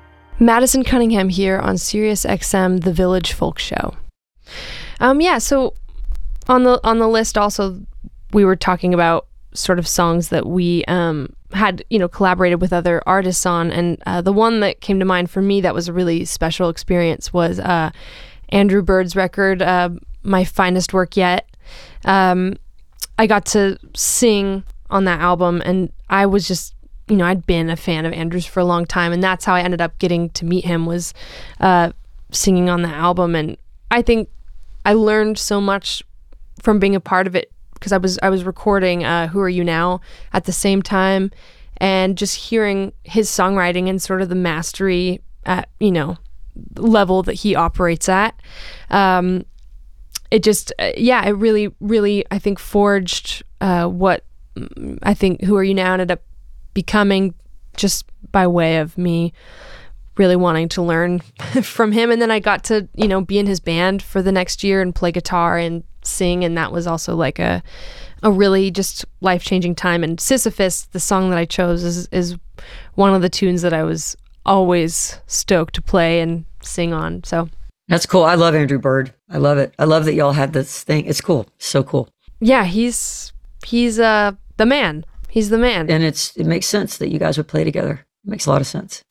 (audio capture from web stream)
11. conversation (amy ray and madison cunningham) (1:29)